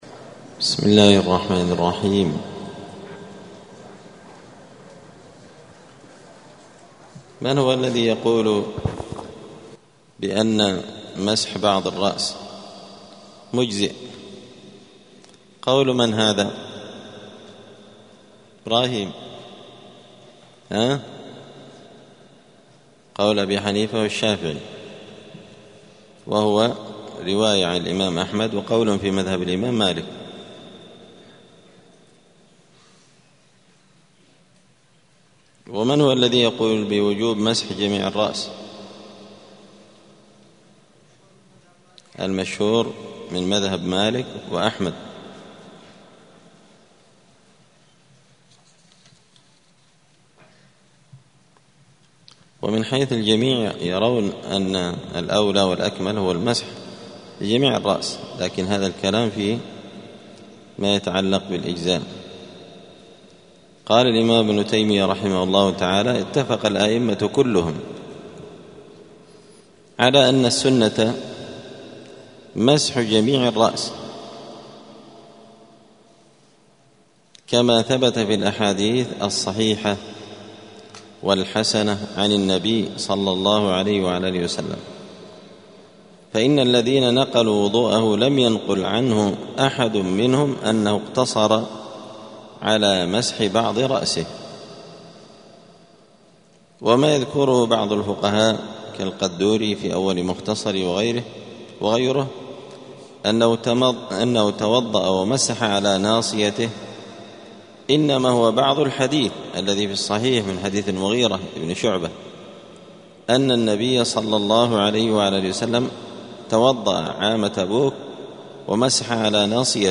دار الحديث السلفية بمسجد الفرقان قشن المهرة اليمن
*الدرس الواحد والثلاثون [31] {باب صفة الوضوء حكم مسح الرأس والأذنين…}*